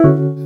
RHODCHORD3-L.wav